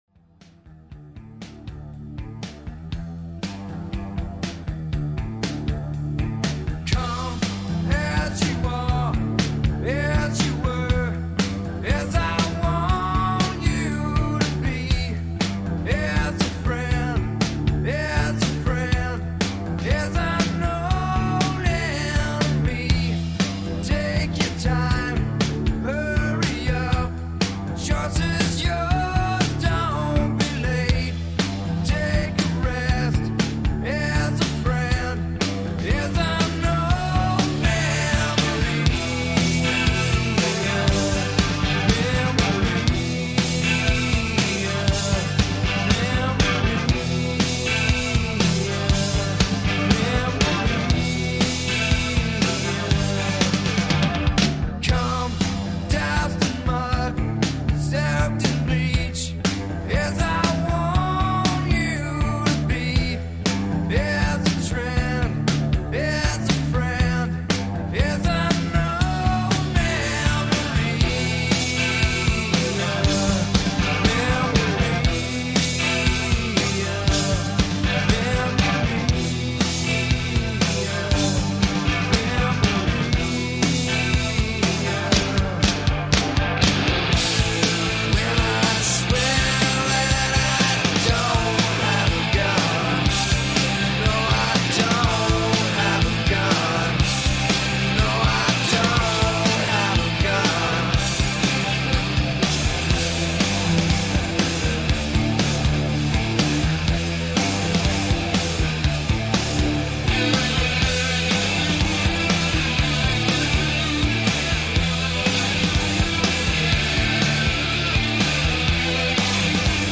Intervista_Vota_Kurt_Cobain.mp3